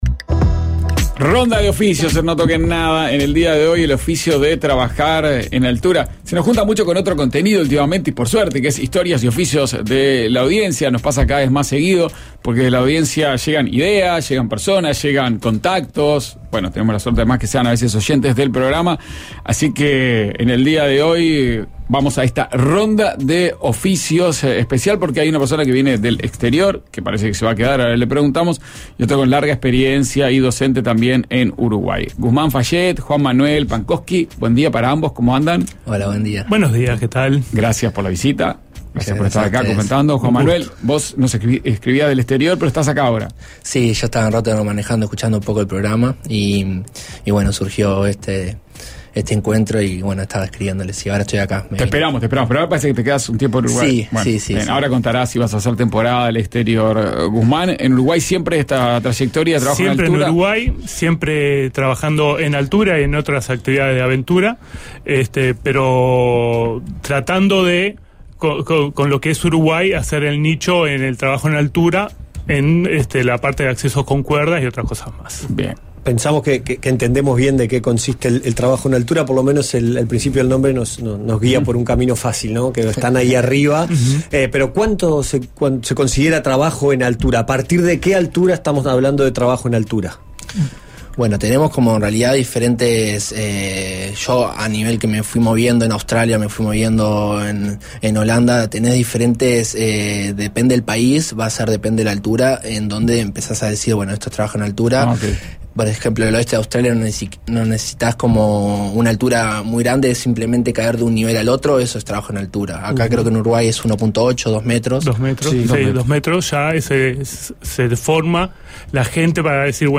Dos carteros nos cuentan su historia en un oficio que era de bolsos pesados de cuero en los 90, y ahora ha cambiado por las nuevas maneras de comunicarse.